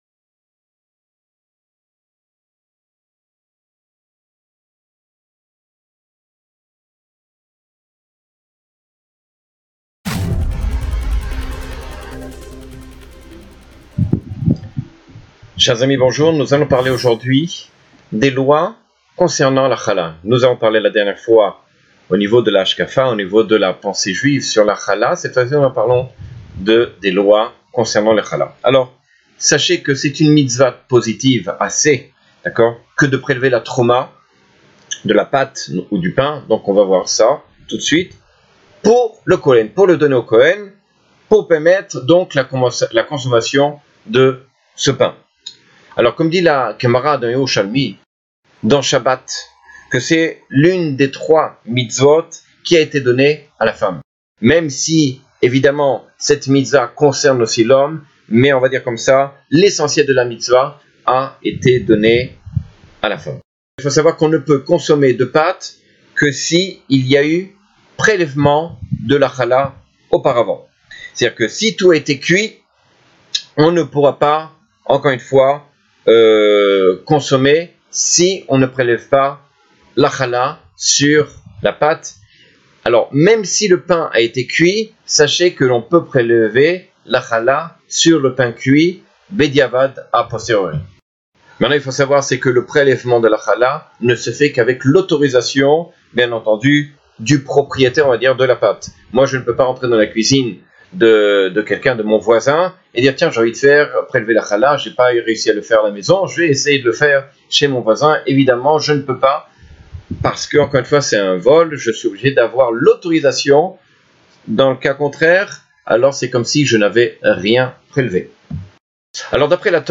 Cours de halakha, loi sur la mitsva de la 'halla.